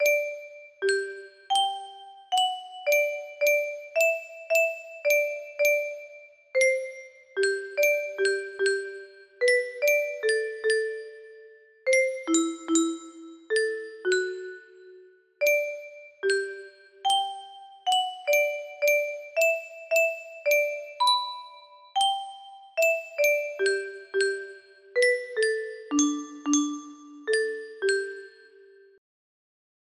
Voyager 2 music box melody